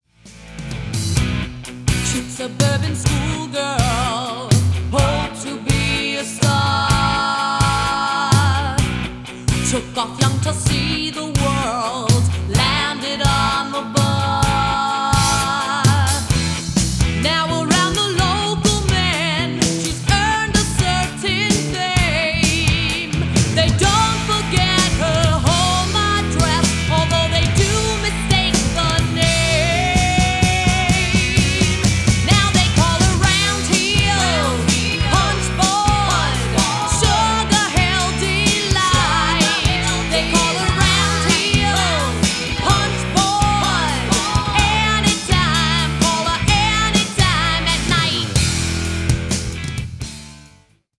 Vocals
Guitar and background vocals
Bass and background vocals
Drums and background vocals